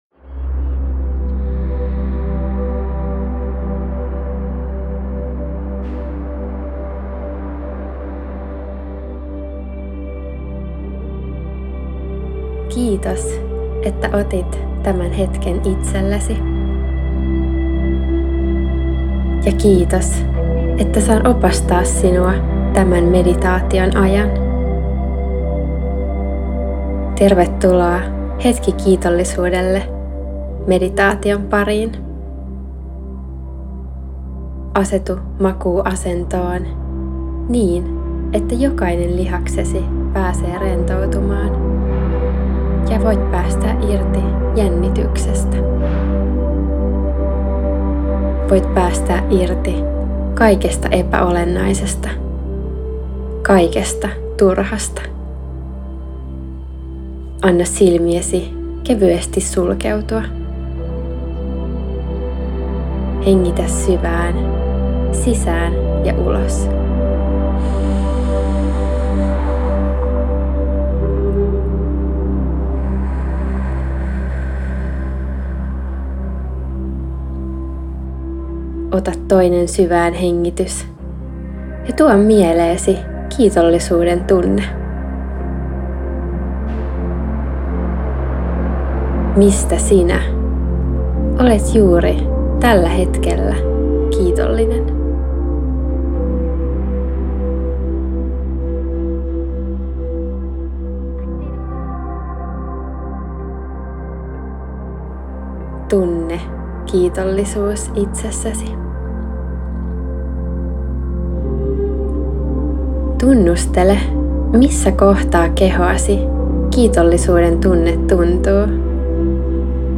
8D-rentoutus